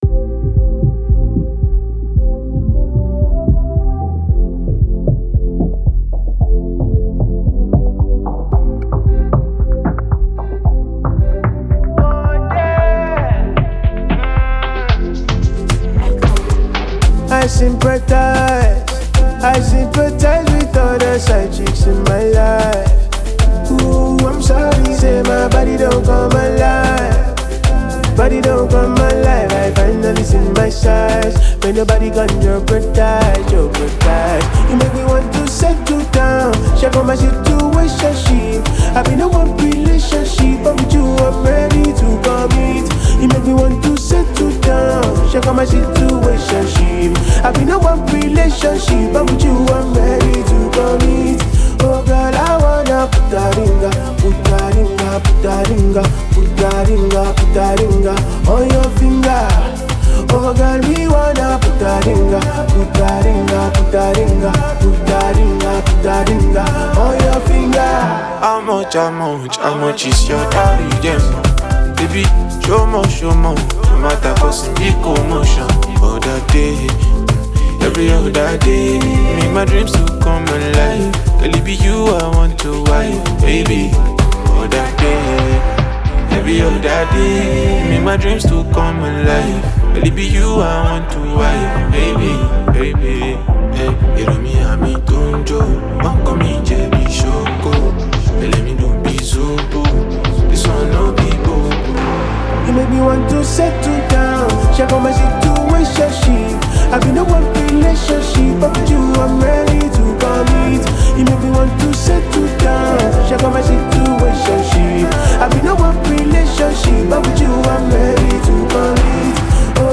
melodious
Afrobeat
soulful vocals